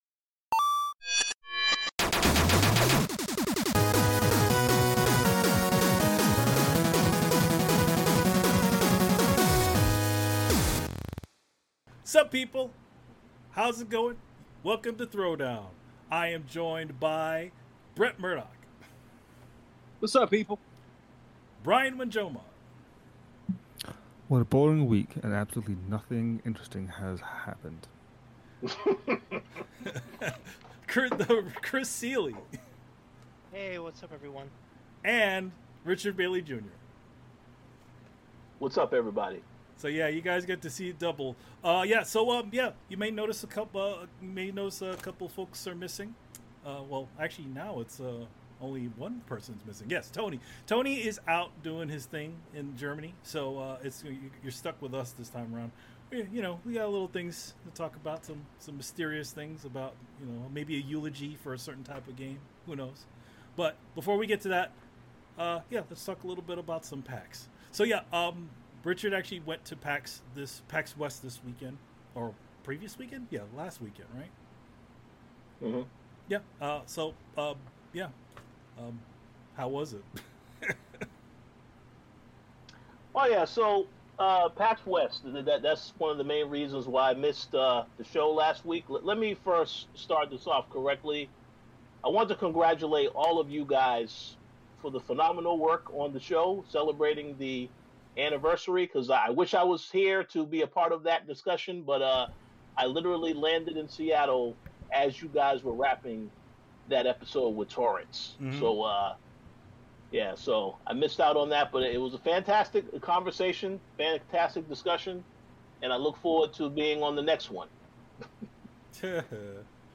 our intro and outro music.